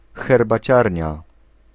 Ääntäminen
Ääntäminen Tuntematon aksentti: IPA: [xɛrbaˈt͡ɕarʲɲa] Haettu sana löytyi näillä lähdekielillä: puola Käännös Konteksti Ääninäyte Substantiivit 1. tea room brittienglanti 2. tea shop 3. teahouse US Suku: f .